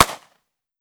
9mm Micro Pistol - Gunshot A 001.wav